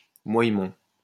Moyemont (French pronunciation: [mwajmɔ̃]